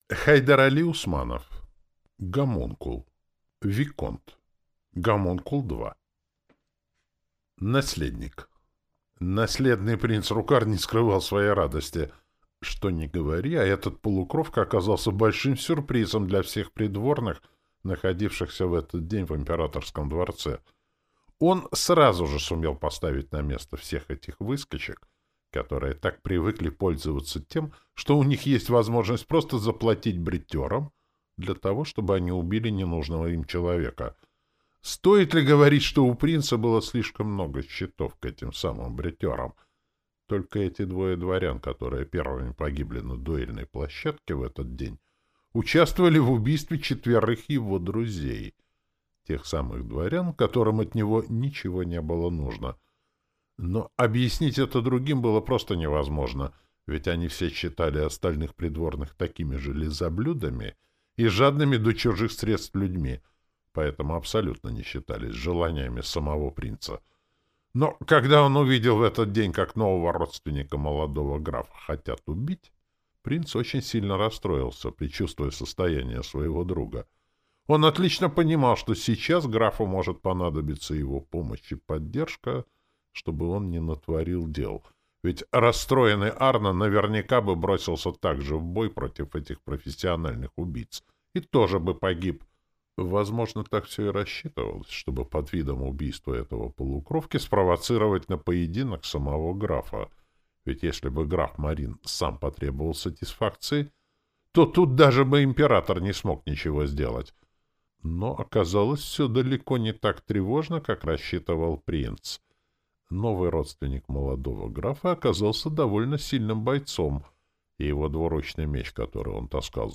Аудиокнига Гомункул. Виконт | Библиотека аудиокниг